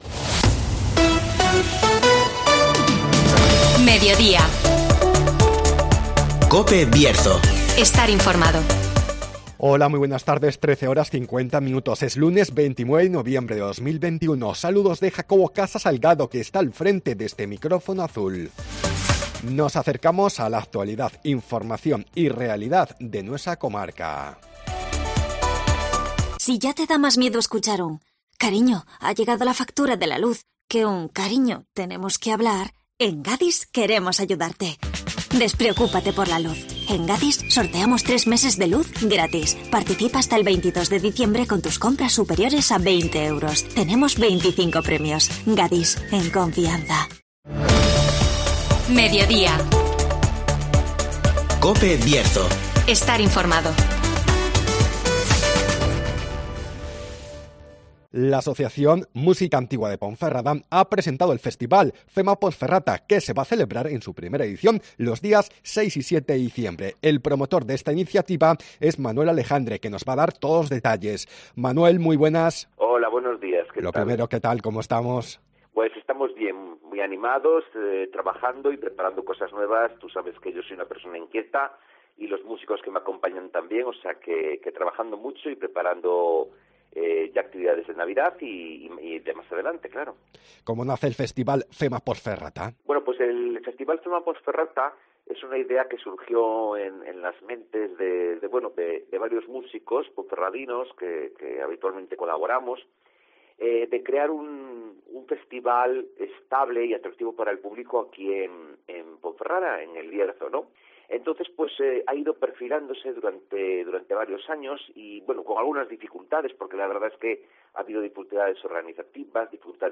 El festival Fema Pons Ferrata propone una fusión entre música antigua y patrimonio arquitectónico (Entrevista